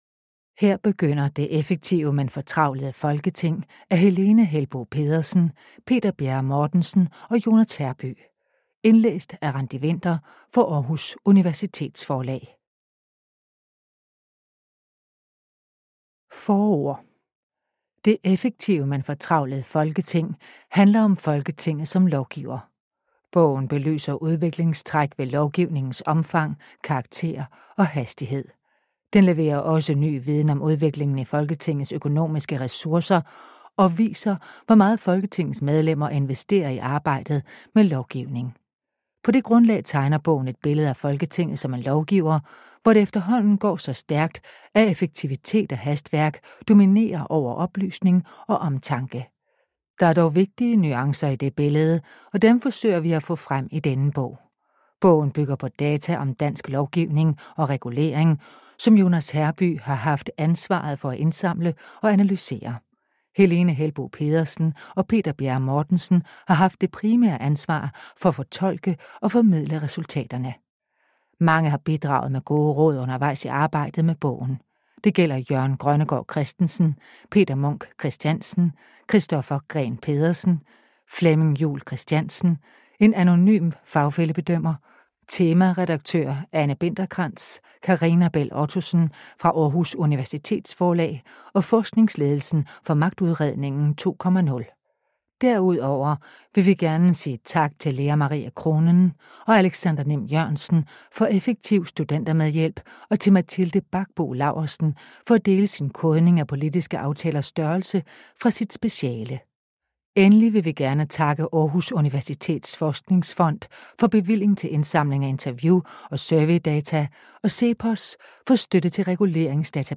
det-effektive-men-fortravlede-folketing-lydbog.mp3